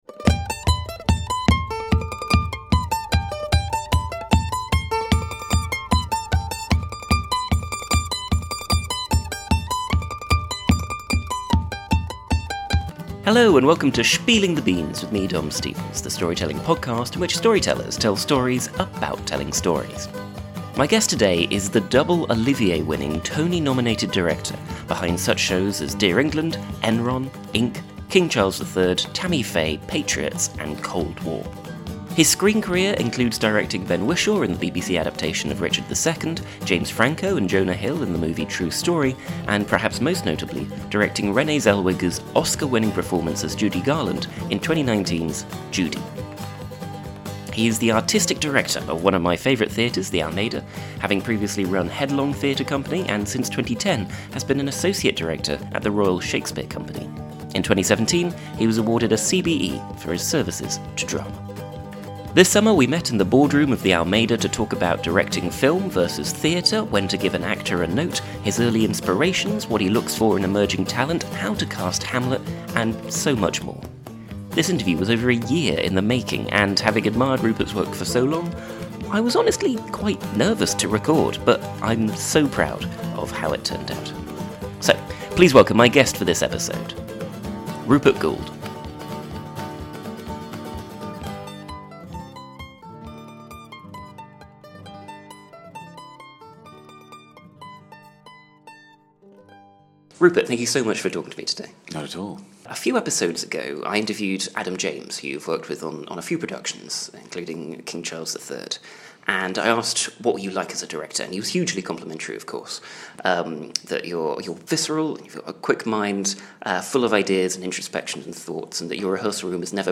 My guest today is Rupert Goold - the double-Olivier-winning, Tony-nominated director behind such shows as Dear England, Enron, Ink, King Charles III, Tammy Faye, Patriots, and Cold War.
This summer, we met in the boardroom of the Almeida (of which he's Artistic Director), to talk about directing film vs theatre, when to give an actor a note, his ea